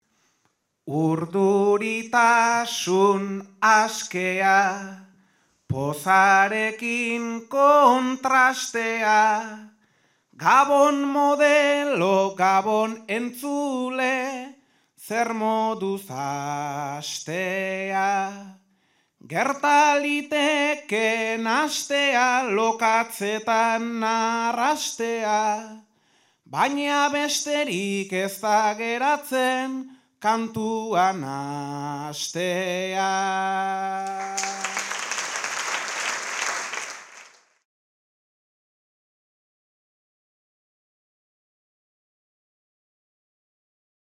Zarautz (Gipuzkoa)
Agurra.